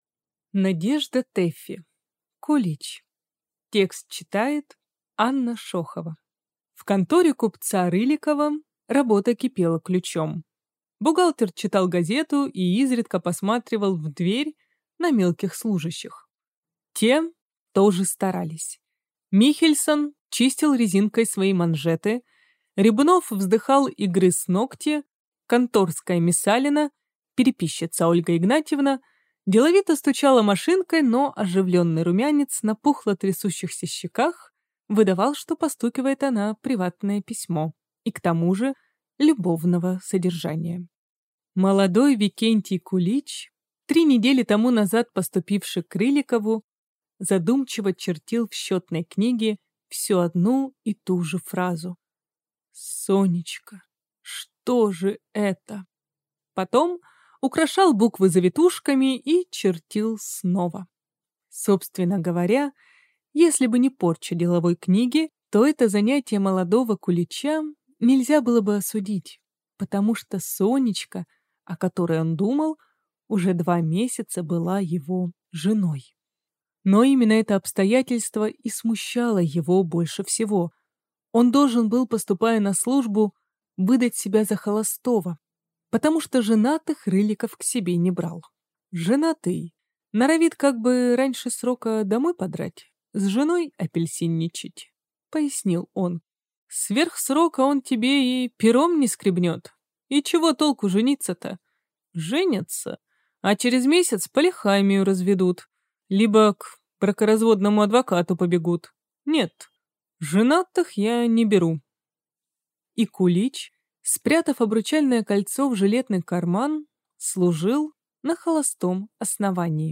Аудиокнига Кулич | Библиотека аудиокниг